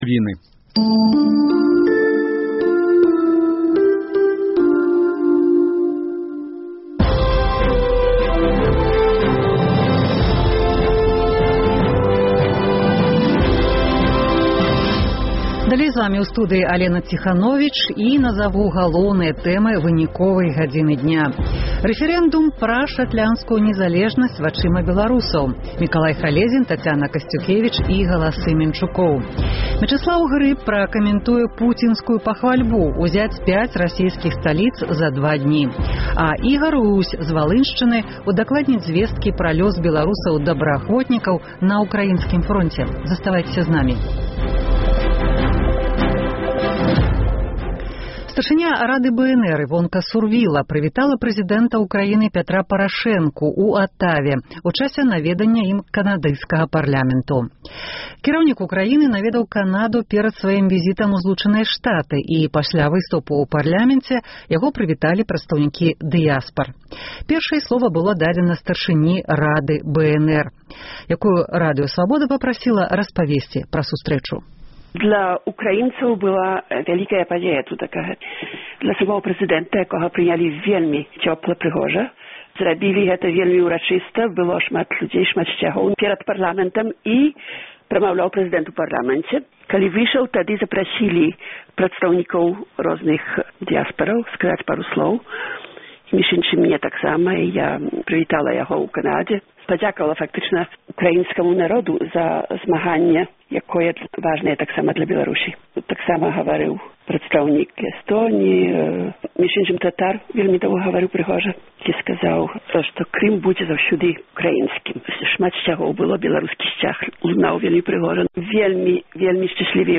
галасы менчукоў. Мечыслаў Грыб камэнтуе пуцінскую пахвальбу ўзяць пяць эўрапейскіх сталіцаў за два дні.